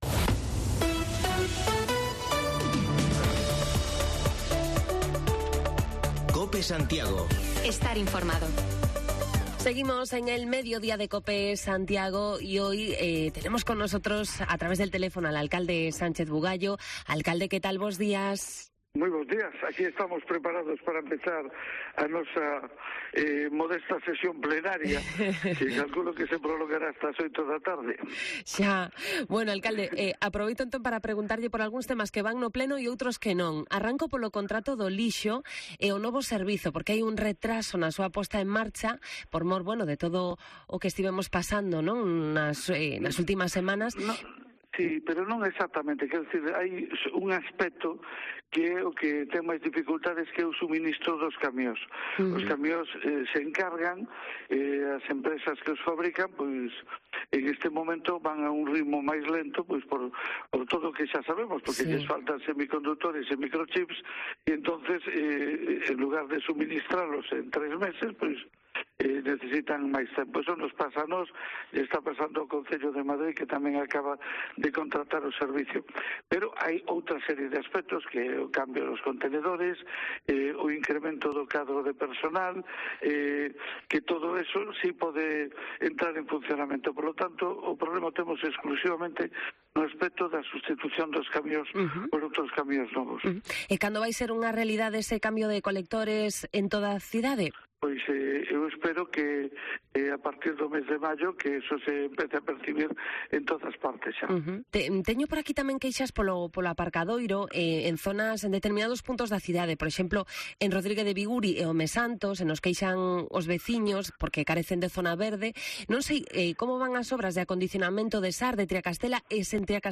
AUDIO: Repasamos la actualidad municipal de Santiago en conversación con el alcalde, Xosé Sánchez Bugallo